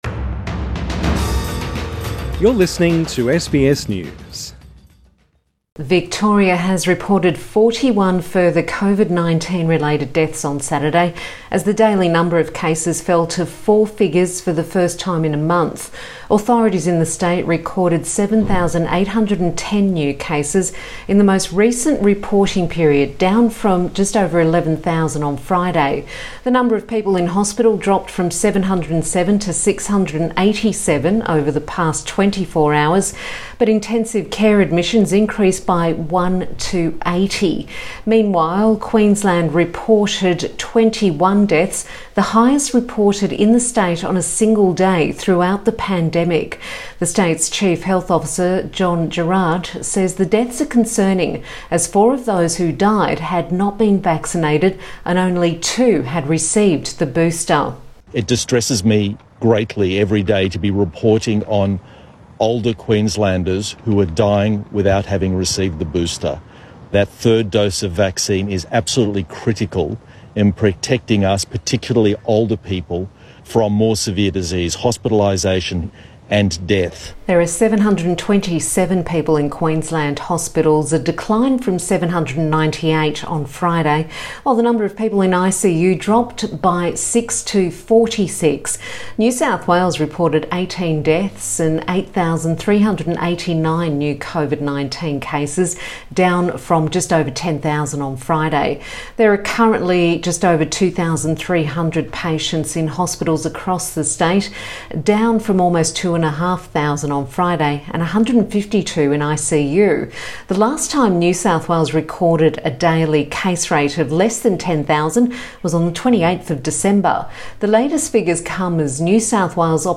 Queensland Chief Health Officer Dr John Gerrard speaks to the media during a press conference.